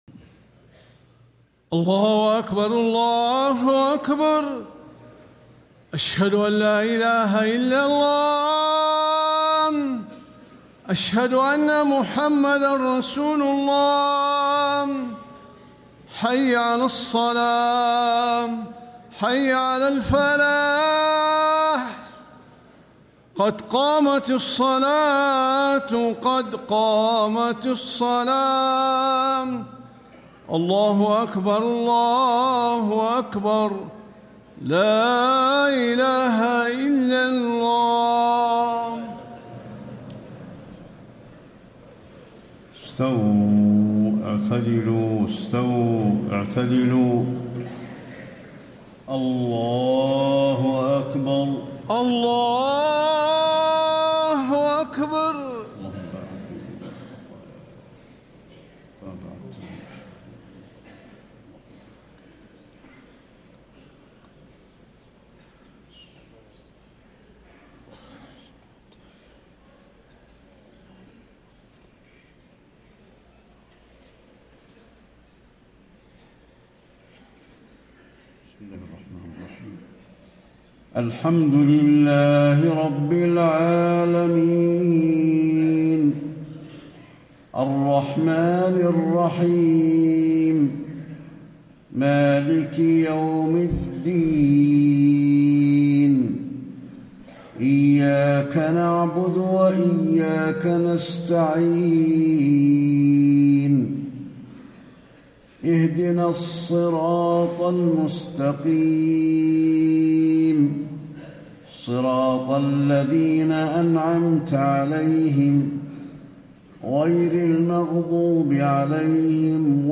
صلاة العشاء 4 - 1 - 1435هـ آخر سورة الفرقان > 1435 🕌 > الفروض - تلاوات الحرمين